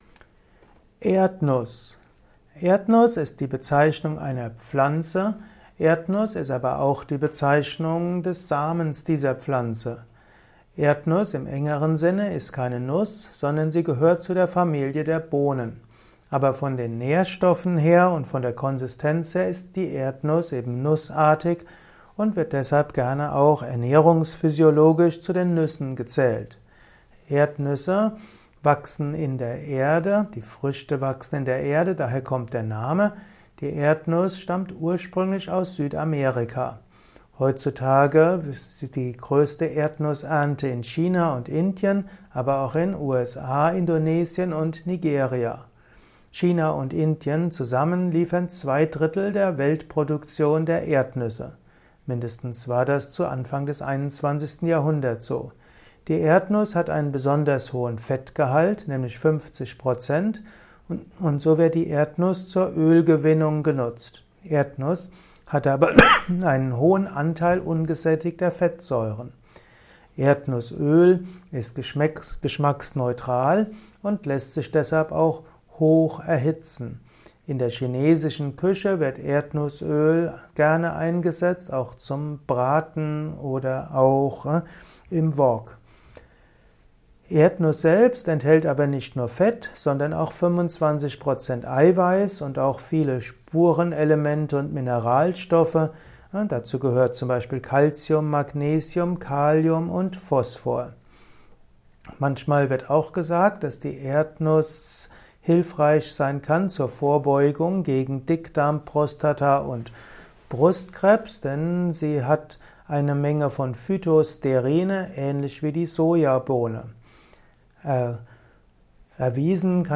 Ein Kurzvortrag zu der Erdnuss